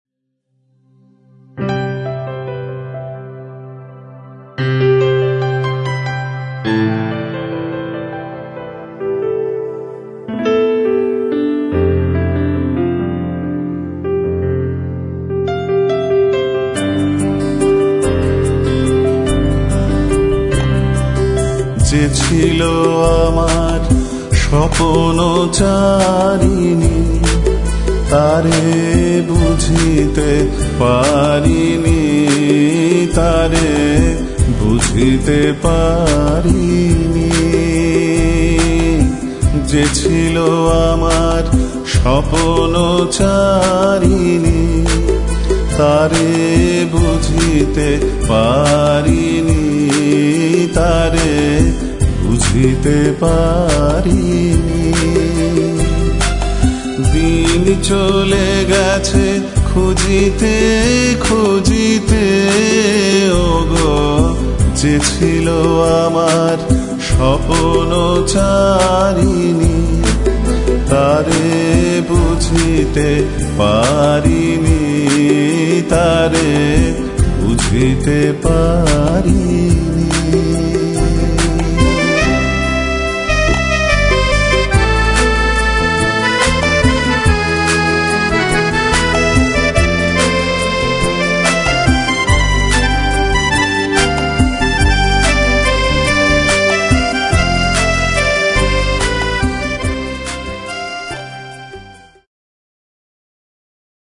Bass, Acoustic and Electric Guitar
Percussion and Digital Kit